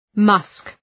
Shkrimi fonetik {mʌsk}